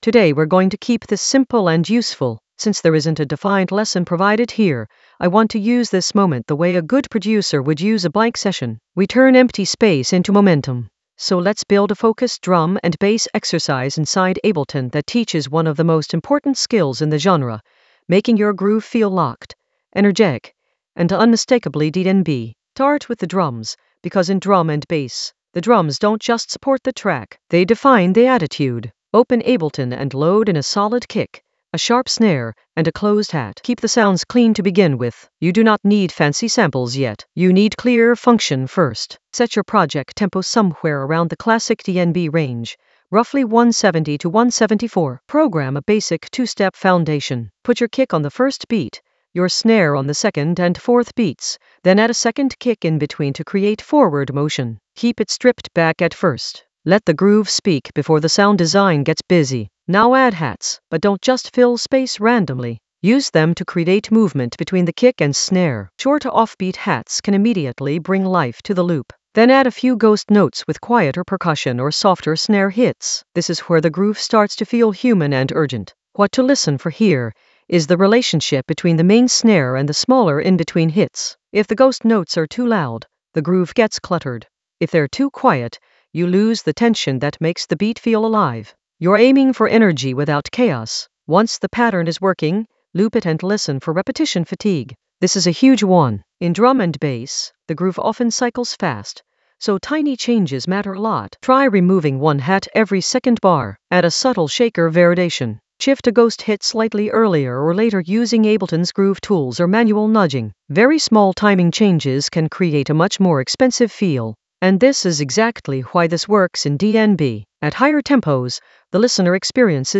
An AI-generated beginner Ableton lesson focused on Alix Perez sub basslines that rattle in the Basslines area of drum and bass production.
Narrated lesson audio
The voice track includes the tutorial plus extra teacher commentary.